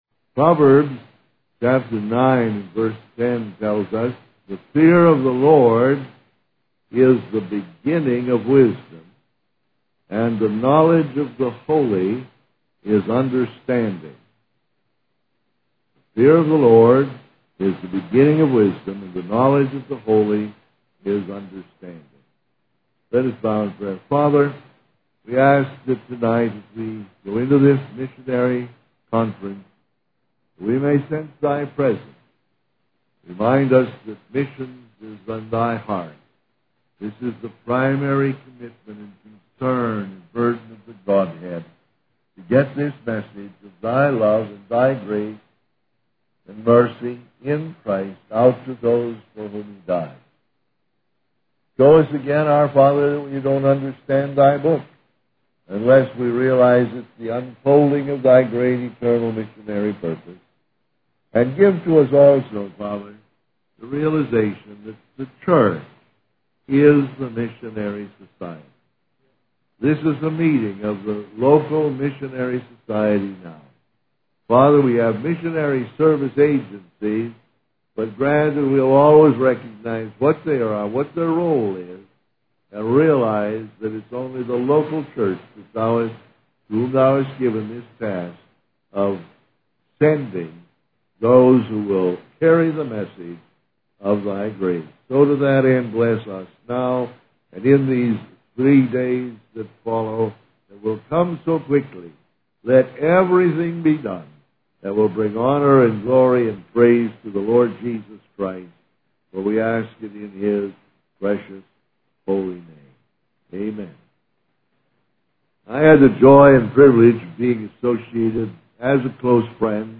In this sermon, the speaker emphasizes the importance of obedience to God's commandments and the need to fulfill the Great Commission. He highlights the lack of burden for the world and the absence of fear of God among believers.